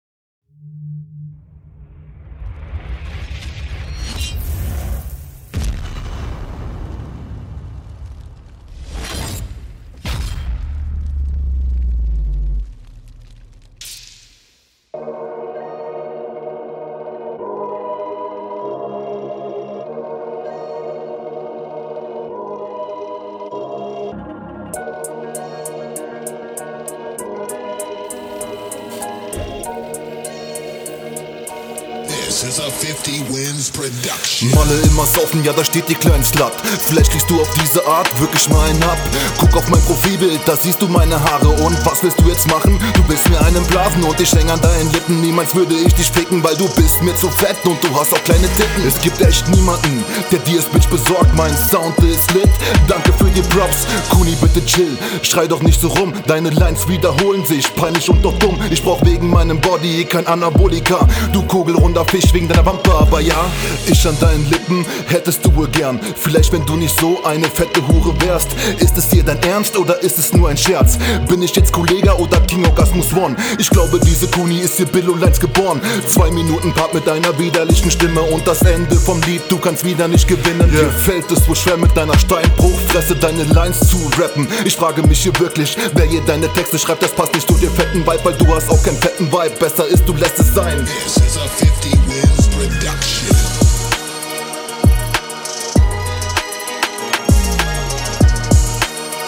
Flow: Finde den Flow schlechter als in der vorherigen Runde.
Stimme sehr deutlich hörbar, aber auch du verlierst irgendwann komplett den Takt.